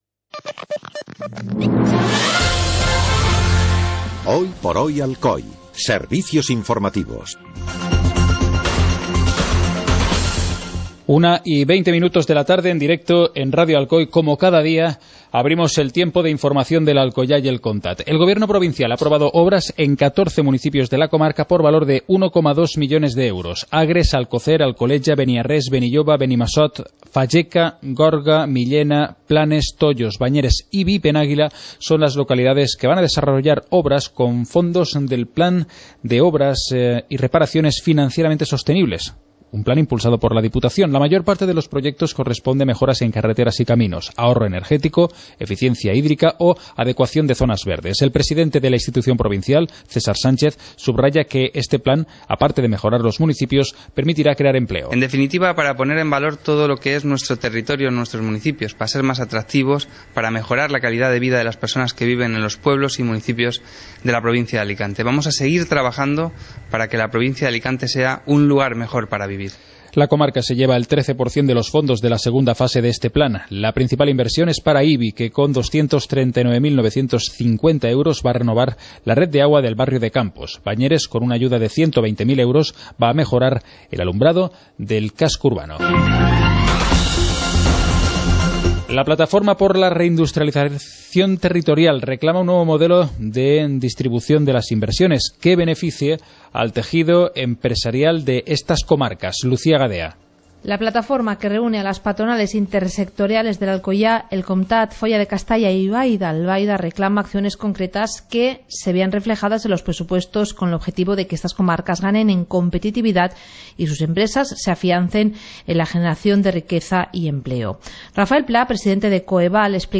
Informativo comarcal - jueves, 01 de octubre de 2015